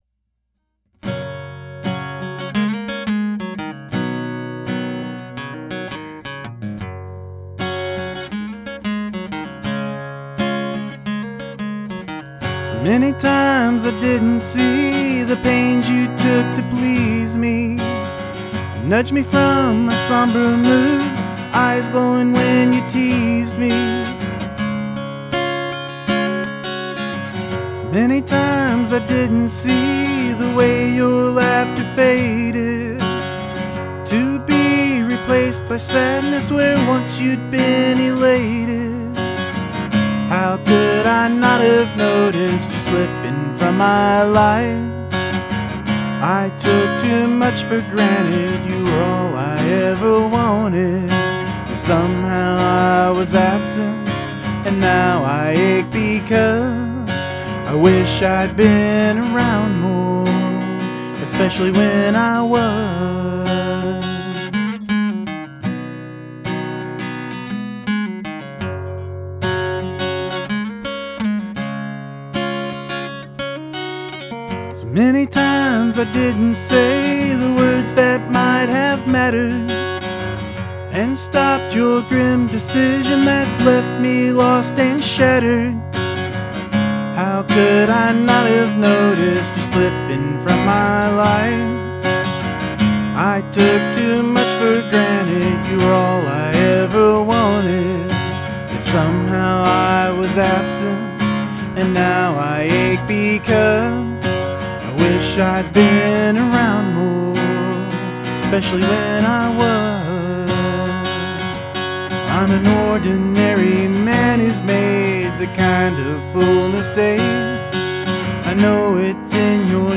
and so meaningful . perfect music and guitar behind it
The music and the vocals/lyrics sound good.
I can't resist an acoustic and vox ballad.
I got the green day run, but loved the way you changed on the chords.